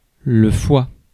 Ääntäminen
Ääntäminen France: IPA: /fwa/ Haettu sana löytyi näillä lähdekielillä: ranska Käännös Konteksti Ääninäyte Substantiivit 1. liver anatomia US Suku: m .